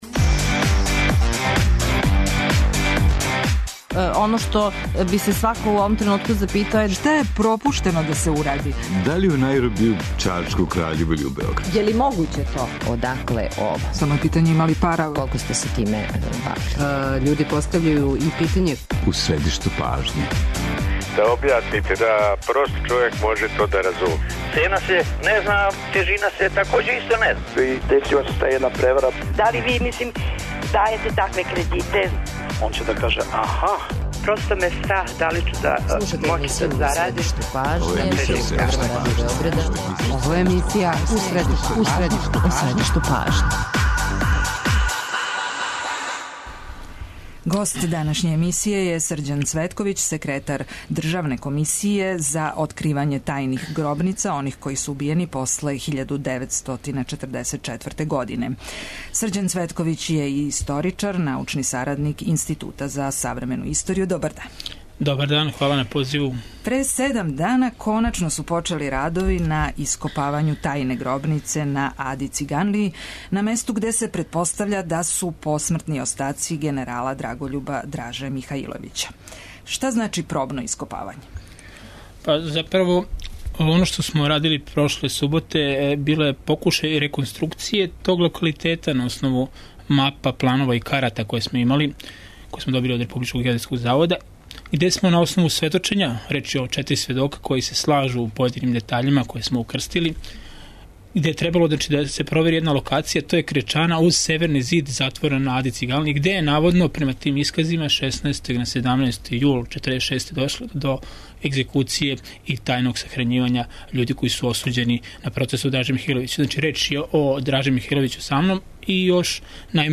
Више детаља о томе чућемо од госта данашње емисије, Срђана Цветковића, секретара Државне комисије за откривање тајних гробница оних који су убијени после 1944. године. Цветковић каже и да је за годину и по дана утврђено постојање преко 200 таквих гробница и да је пописано 25 хиљада особа које су стрељане и сахрањене у масовним гробницама после 12. септембра 1944. године.